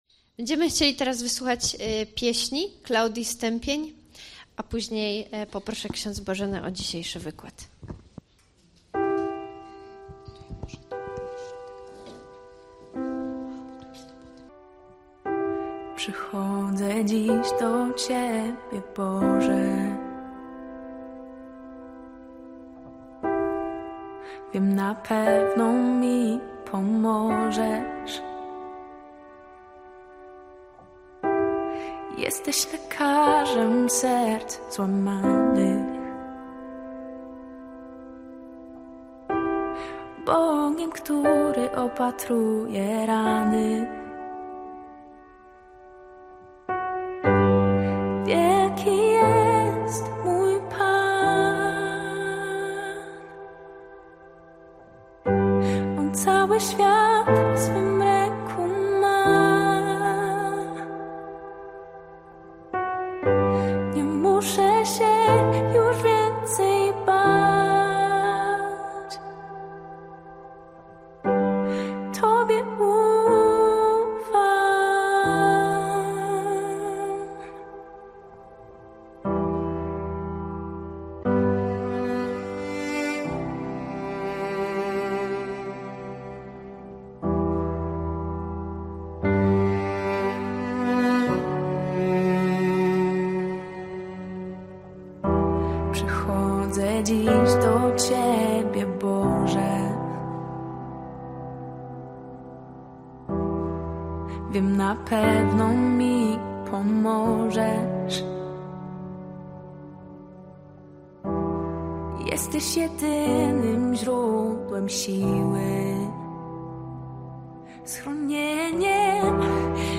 SPOTKANIE DLA MAŁŻEŃSTW i NARZECZONYCH – Rozwiązywanie konfliktów